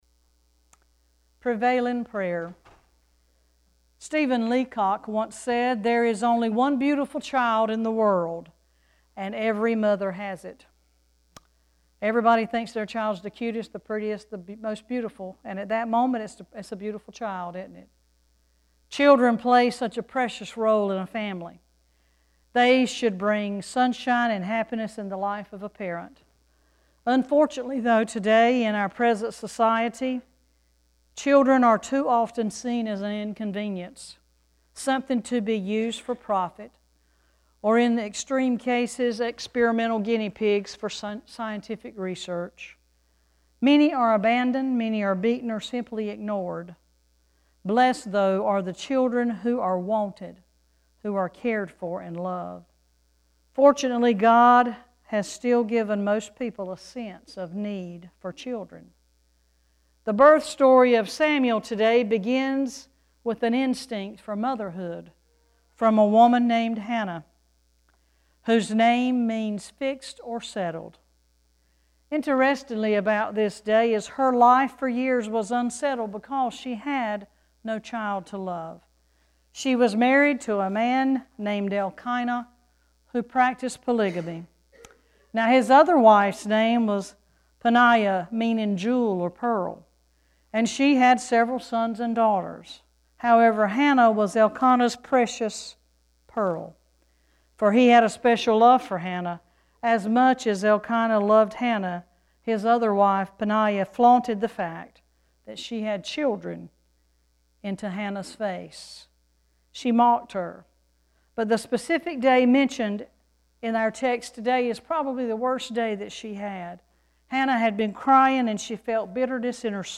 11-18-Sermon.mp3